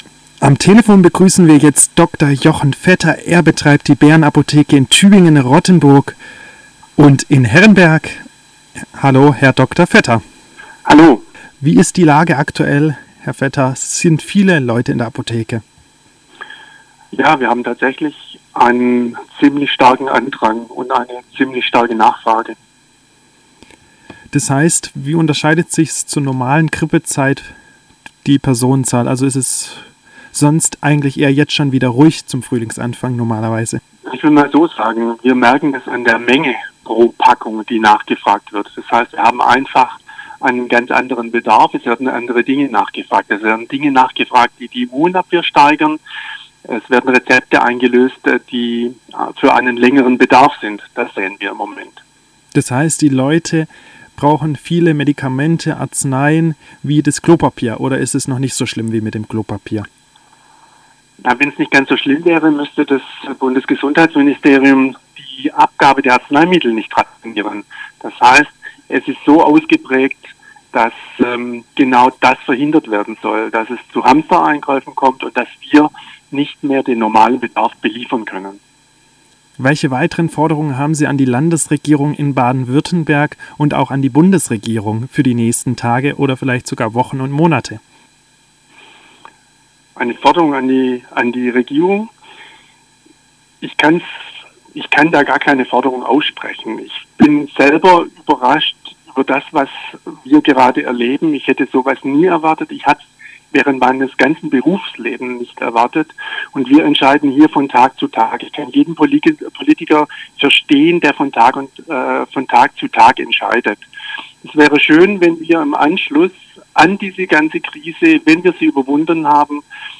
All Inclusive hat mit zwei regionalen Experten gesprochen.